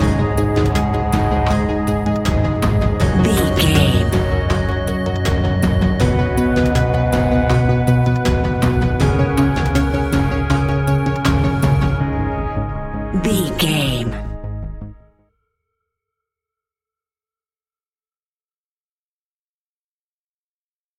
royalty free music
Aeolian/Minor
A♭
ominous
dark
eerie
synthesizer
drum machine
horror music